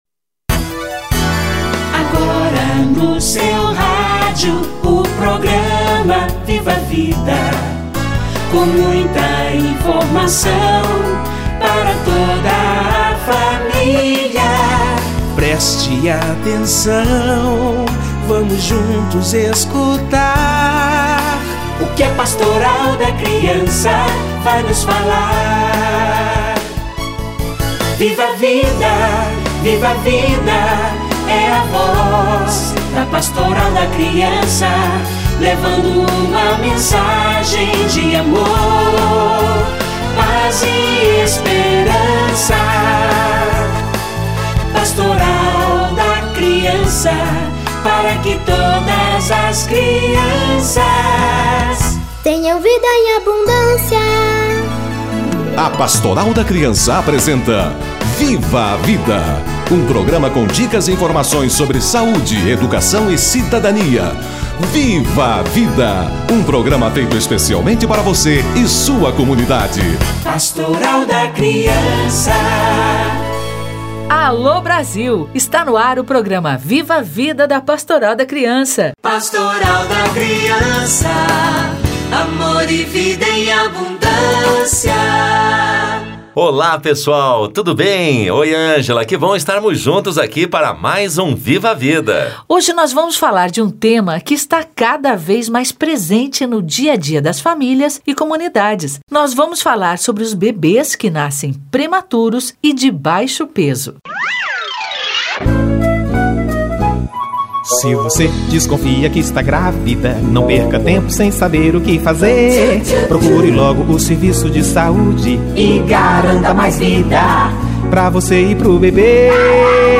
Bebês prematuros - Entrevista